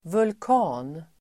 Uttal: [vulk'a:n]